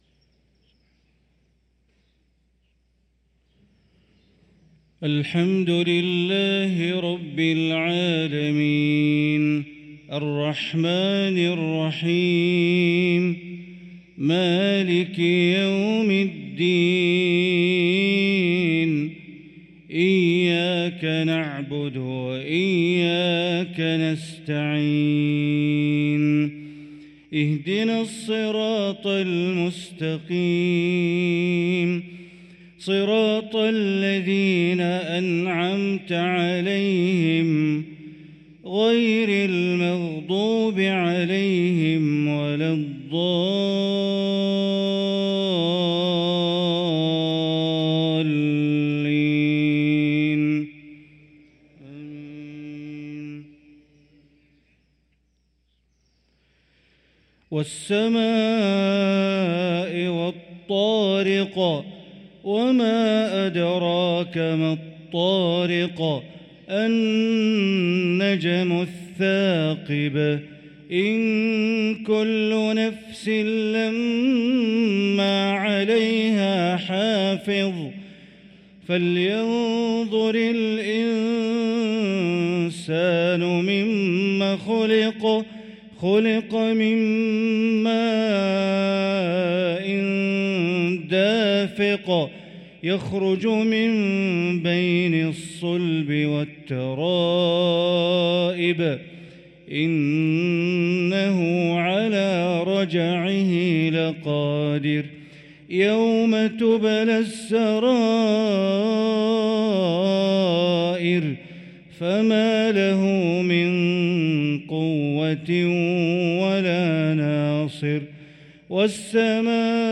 صلاة المغرب للقارئ بندر بليلة 30 ربيع الآخر 1445 هـ
تِلَاوَات الْحَرَمَيْن .